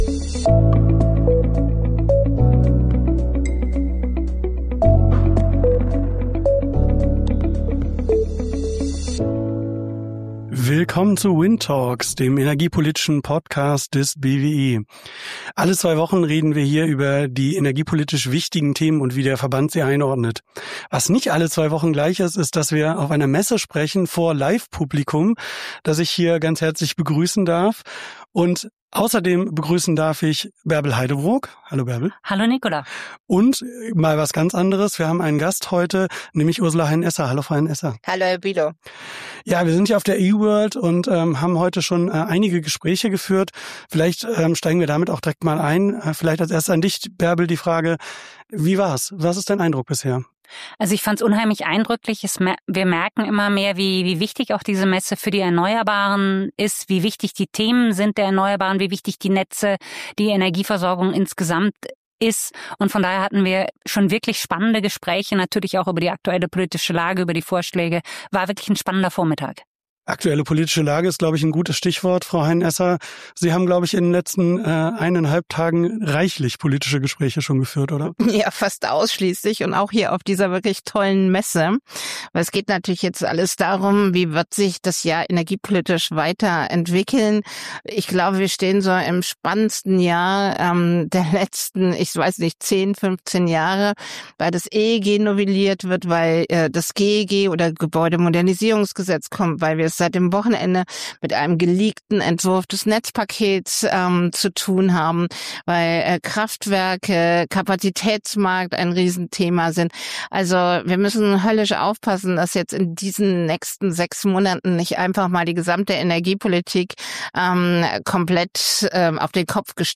Die zentrale Frage: Drohen strengere Anschlussbedingungen und damit neue Hürden für den Ausbau der Erneuerbaren? Mit genau diesem Thema waren wir auf der E-world energy & water in Essen und haben dort eine Live-Folge WindTalks aufgenommen.